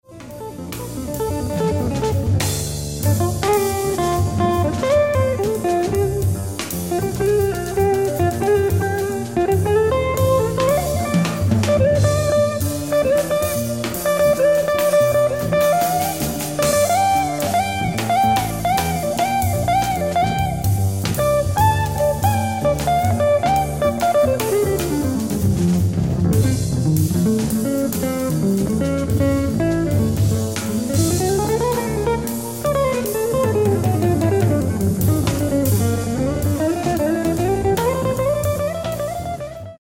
ライブ・アット・厚生年金会館、東京 01/28/1992
※試聴用に実際より音質を落としています。